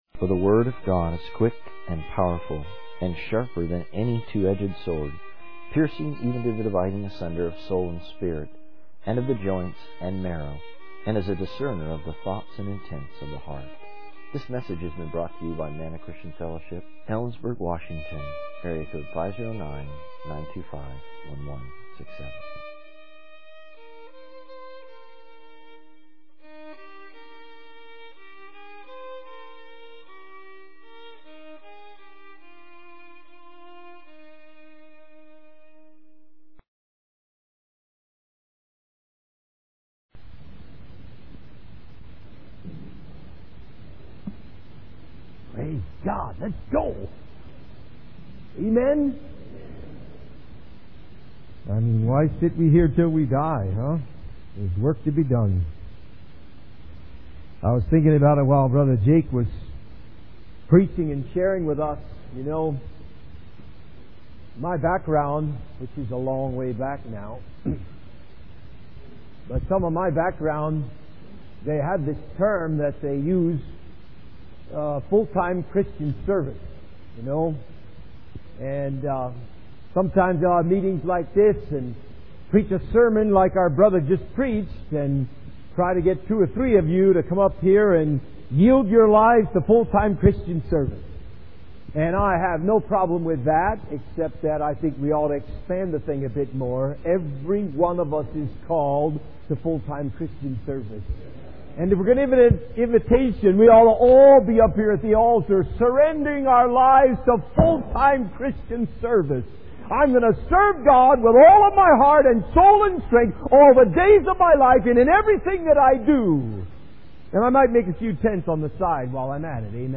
In this sermon, the preacher emphasizes the importance of listening to the word of God with a sincere and open heart.